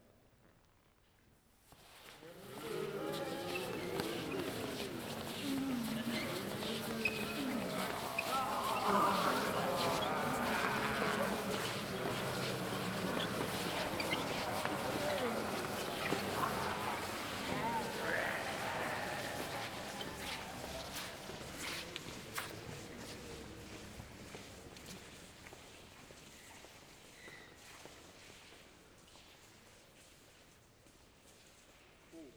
zombiehorde.wav